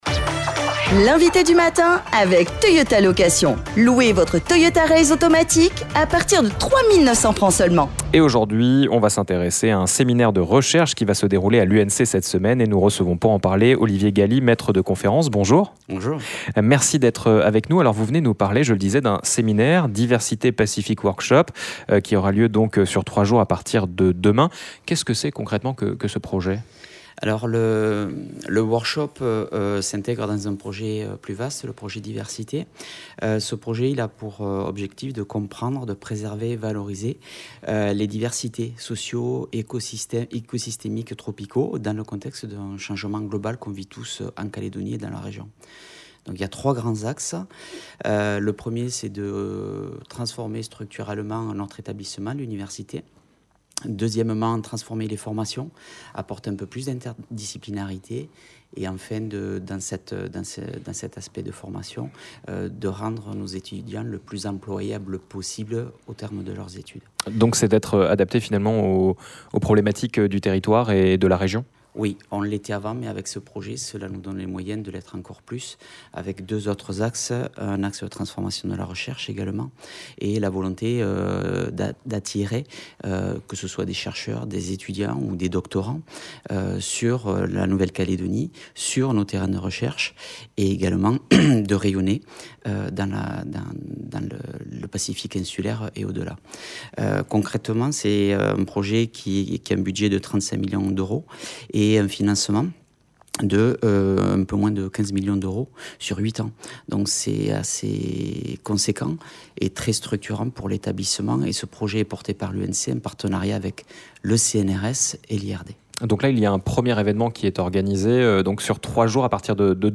L'INVITÉ DU MATIN